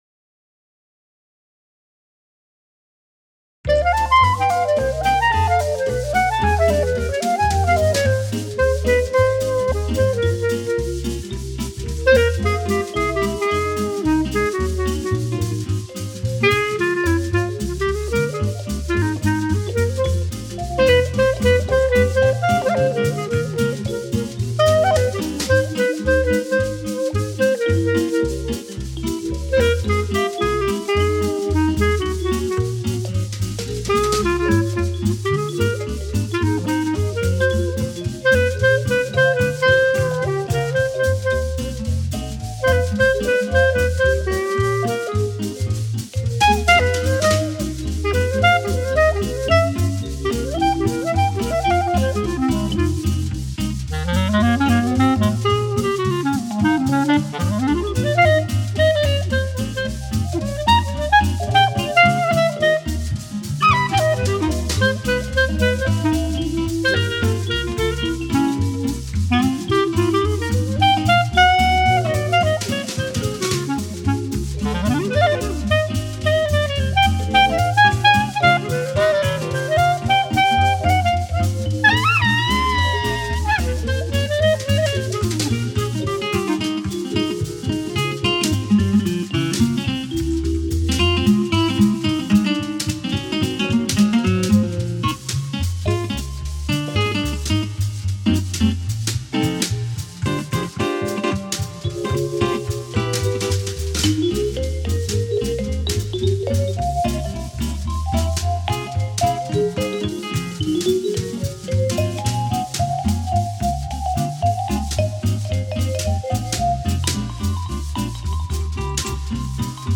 Multi-tracking